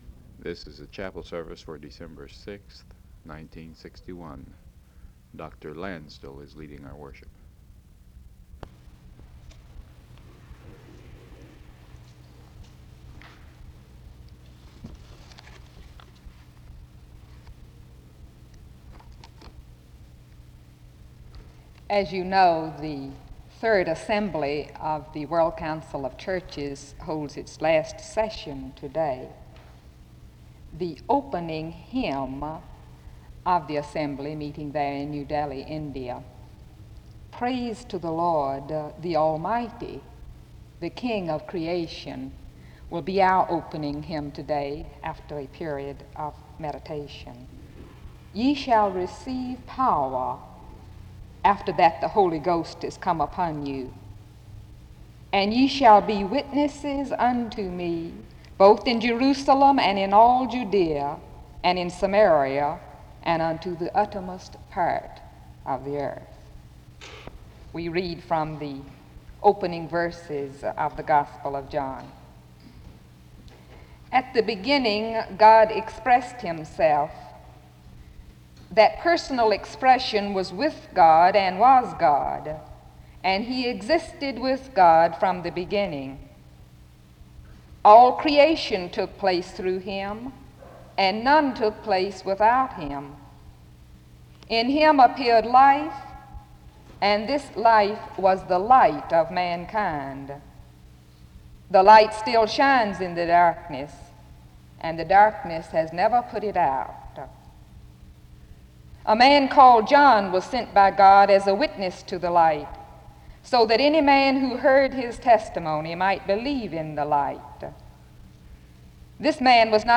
Download .mp3 Description The service begins with an introduction on missions with the reading of Matthew 28:18-20 and John 1:1-14 (00:00-03:10) before praying (03:11-06:25).
SEBTS Chapel and Special Event Recordings SEBTS Chapel and Special Event Recordings